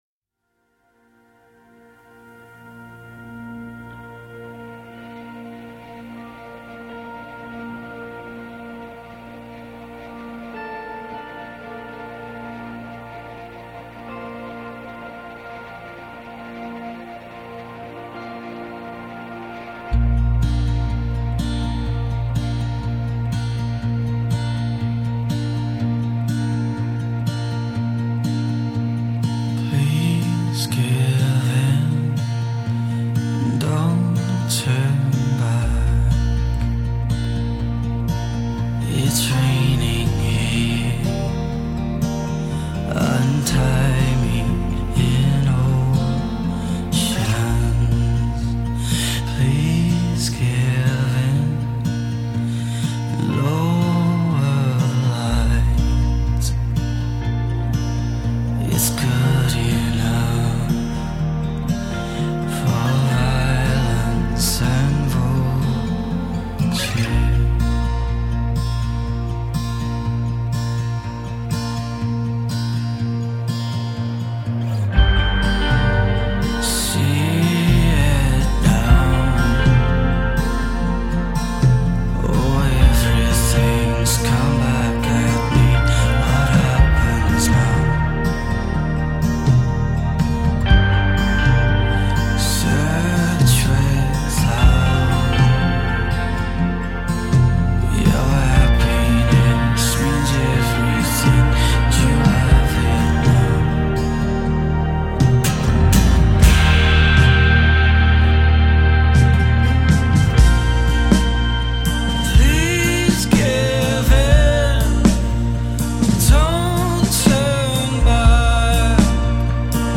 Emerging indie five-piece